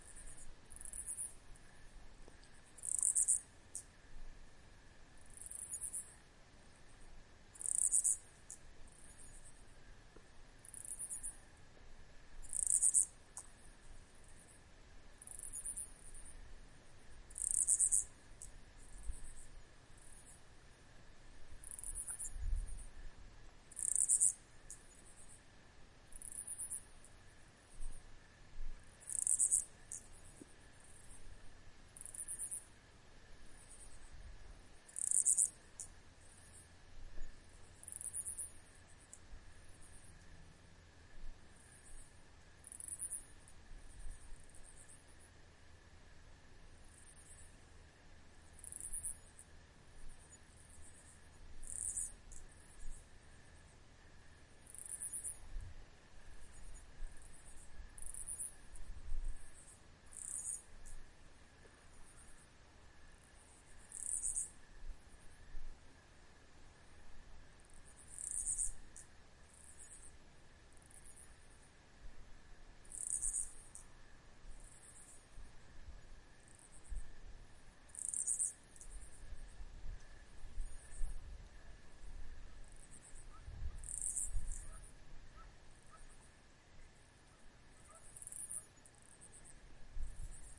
随机 " 蟋蟀的乡村田野之夜，附近有沙哑的蝉声和音调，偶尔有狗叫声2
描述：蟋蟀国家田野之夜与附近的蝉蝉和音调+偶尔吠叫dog2.flac
Tag: 蟋蟀 晚上 国家